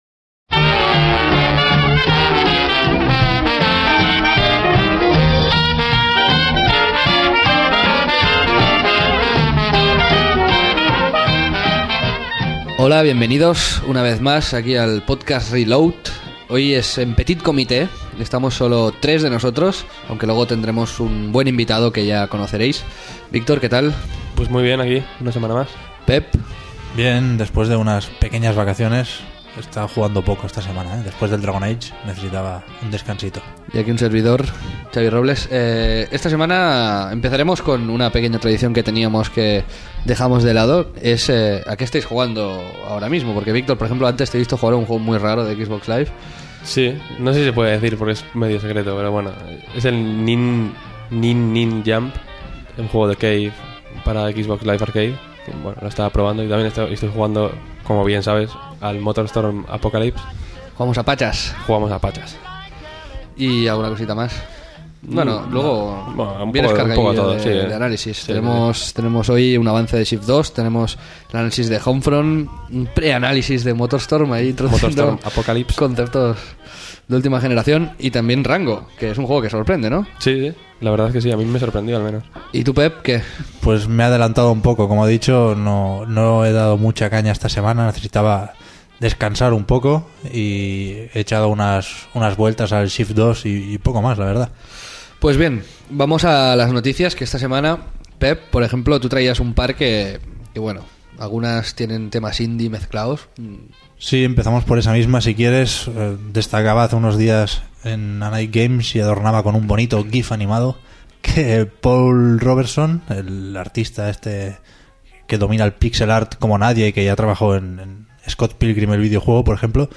Descargar MP3 / Reproducir ahora A ritmo de jazz empezamos un programa más largo de lo que esperábamos. Y es que el tiempo pasa rápido cuando se discute sobre algo como las notas.